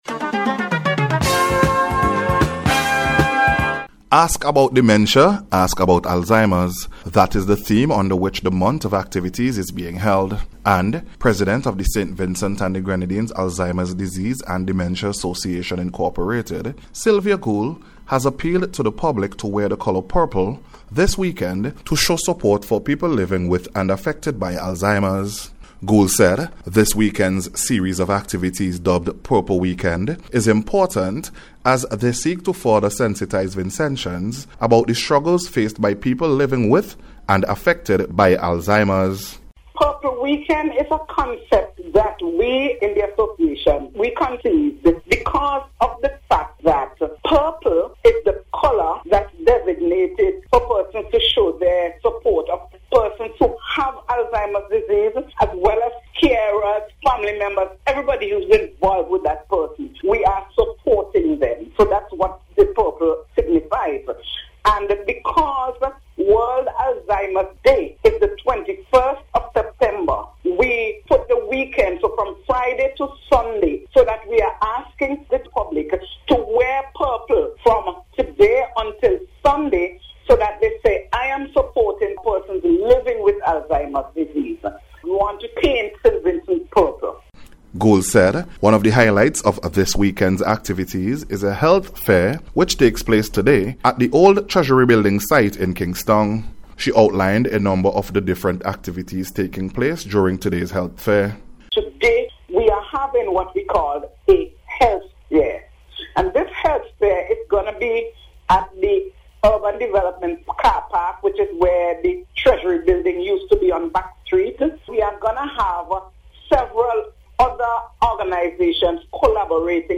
NBC’s Special Report- Friday 19th September,2025